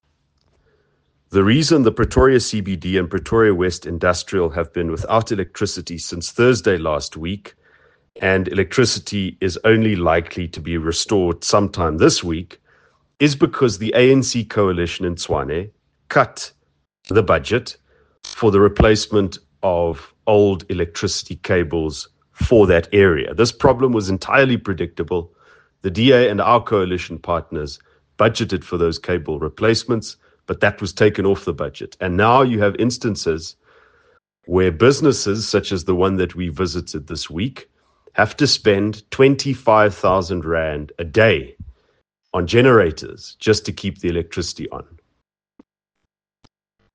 Afrikaans soundbites by Ald Cilliers Brink
Cilliers-Brink_ENG_CBD-power-outage-due-to-poor-budget-planning.mp3